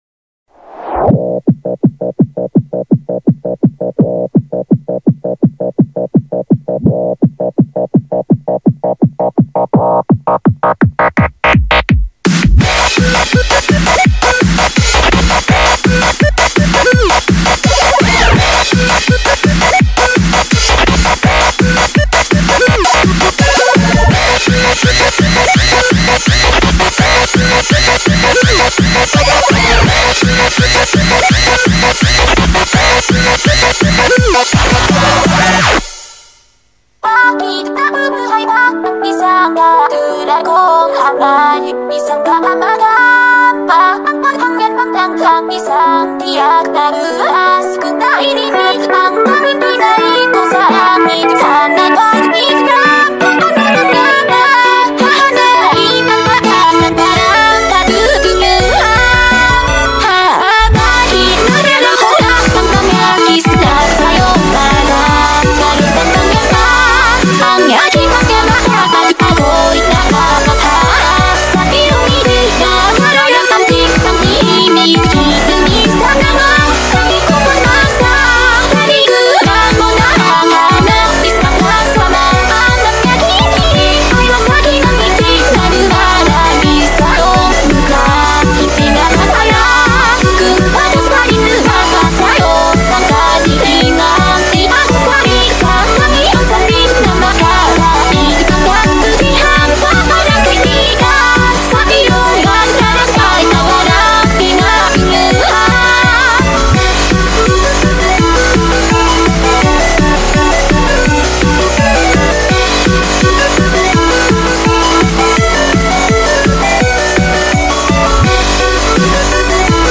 BPM83-167
Audio QualityPerfect (Low Quality)